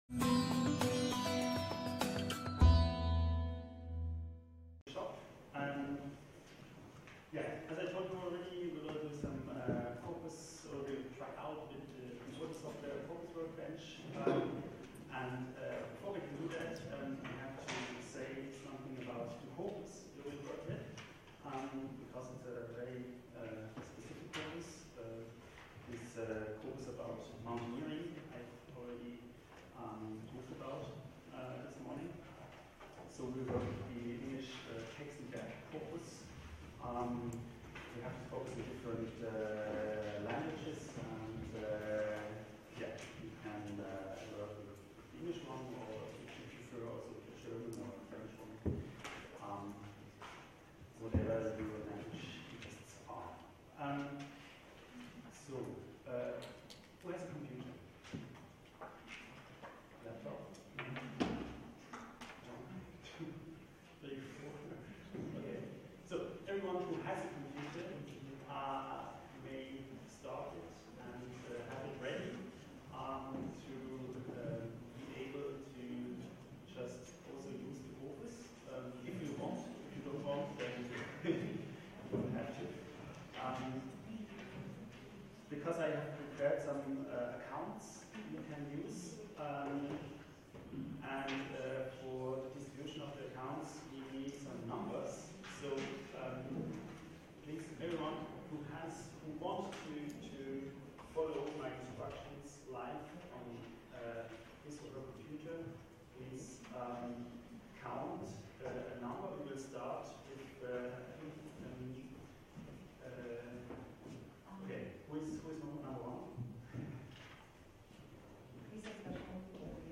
Ort Ivane Javakhishvili Tbilisi State University Datum 24.02.2016 @ 10:04